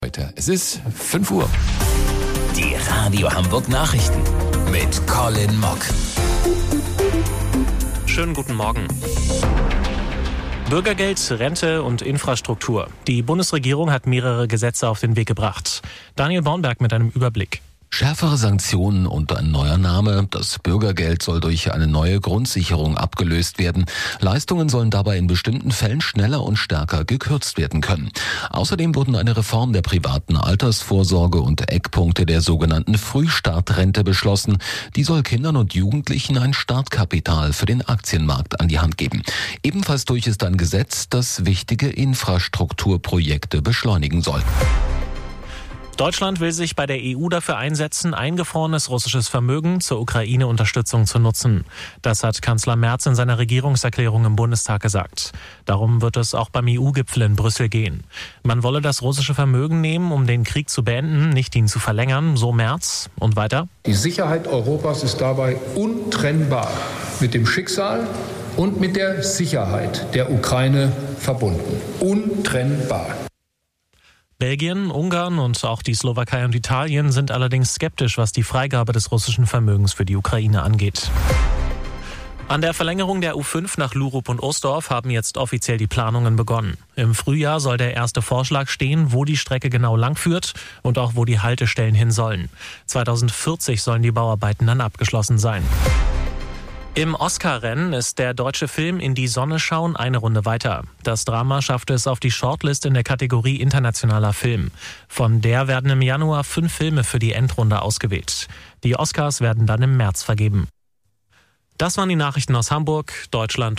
Radio Hamburg Nachrichten vom 18.12.2025 um 05 Uhr